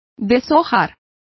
Complete with pronunciation of the translation of defoliates.